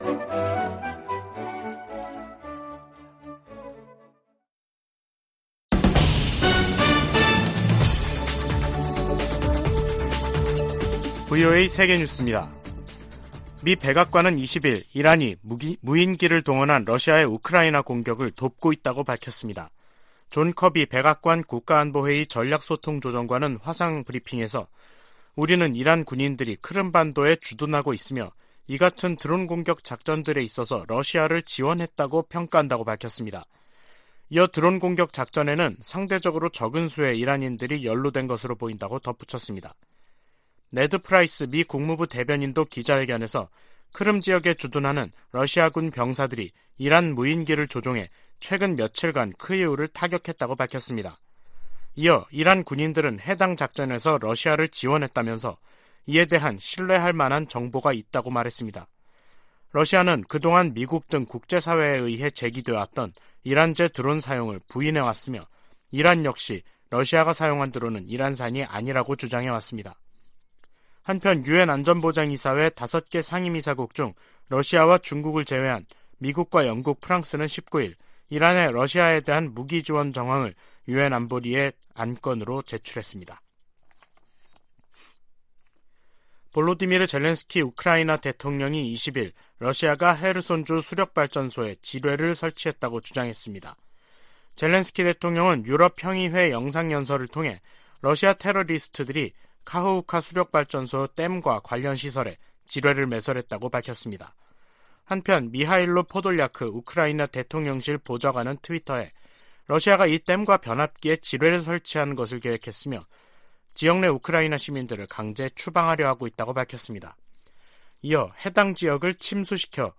VOA 한국어 간판 뉴스 프로그램 '뉴스 투데이', 2022년 10월 21일 3부 방송입니다. 조 바이든 미국 대통령은 모든 방어역량을 동원해 한국에 확장억제를 제공하겠다는 약속을 확인했다고 국무부 고위당국자가 전했습니다. 미 국방부가 북한의 잠재적인 추가 핵실험 준비 움직임을 주시하고 있다고 밝혔습니다. 미국 일각에서는 북한의 핵 보유를 인정하고 대화로 문제를 풀어야 한다는 주장이 나오지만 대다수 전문가들은 정당성을 부여해서는 안된다는 입장입니다.